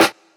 SCOTT_STORCH_snare_g_unit.wav